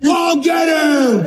Untitled video - Made with Clipchamp [vocals].mp3